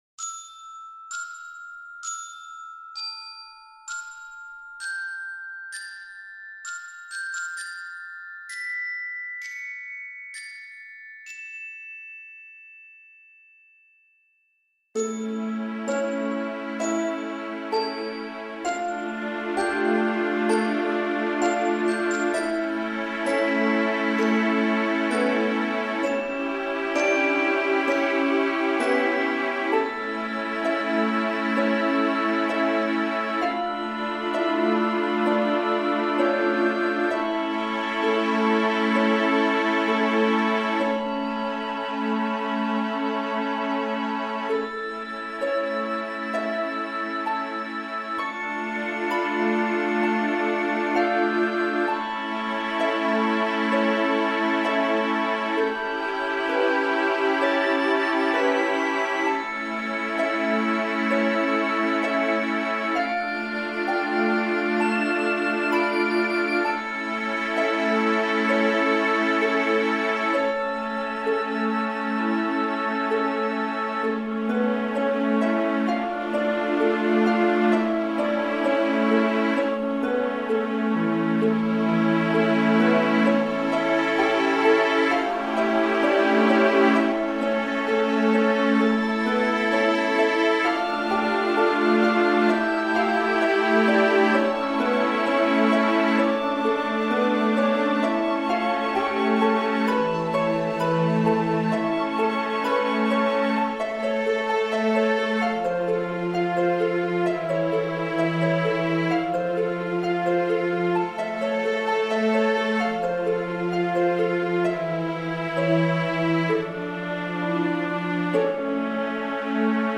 'ambient orchestral'